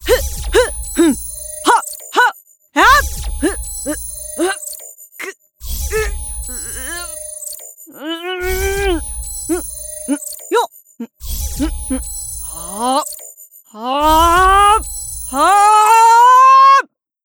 MobCharacterVoicePack Female1 | Voices Sound FX | Unity Asset Store
Demo_Female1_Action.wav